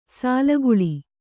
♪ sālaguḷi